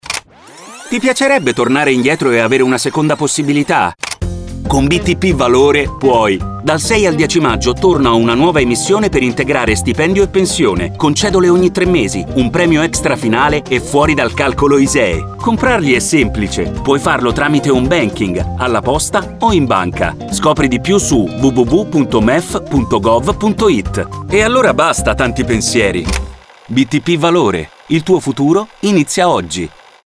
Lo spot radio
Il voiceover sottolinea che è possibile comprarli dal 6 al 10 maggio tramite banking (se abilitato alle funzioni di trading online) negli uffici postali o in banca.